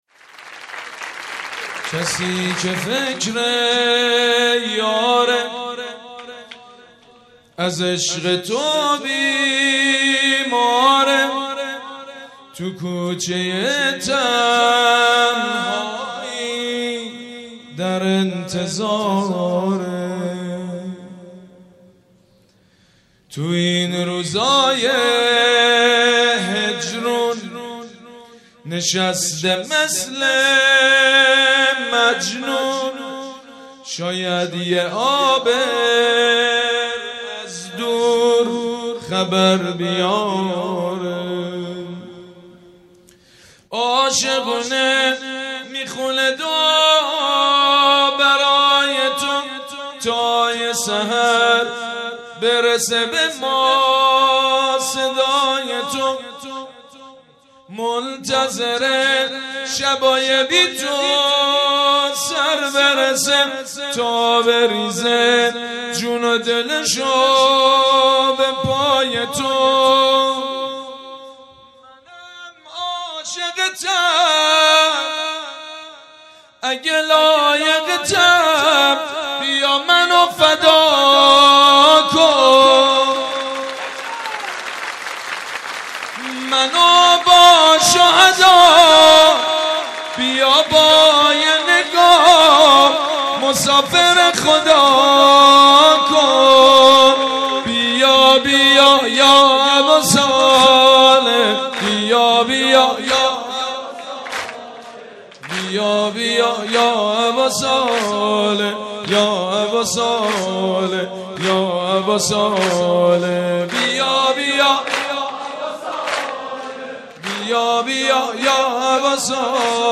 مناسبت : ولادت حضرت مهدی عج‌الله تعالی‌فرج‌الشریف
مداح : سیدمجید بنی‌فاطمه قالب : سرود